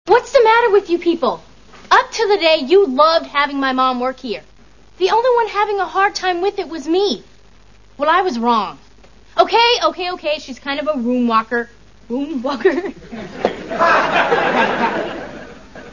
Bloopers.
Tia mispronouncing "room rocker".   62 Kb